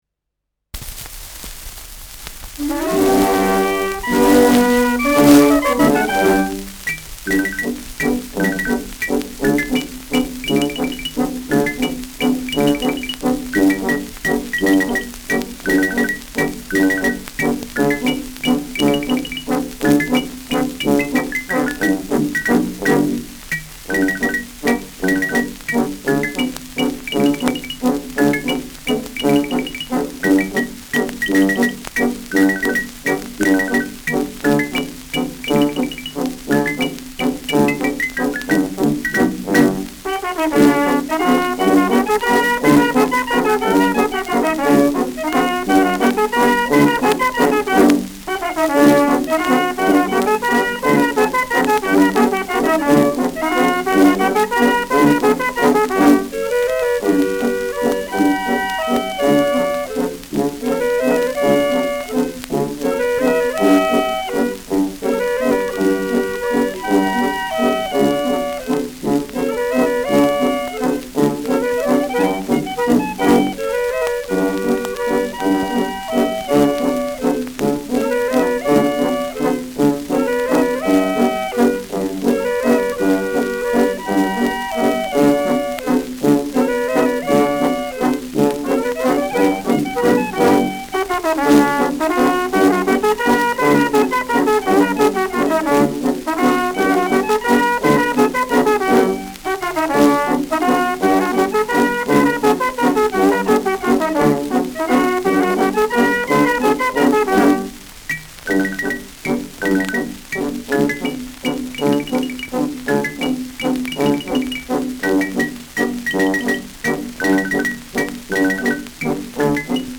Schellackplatte
Stärkeres Grundrauschen : Vereinzelt leichtes Knacken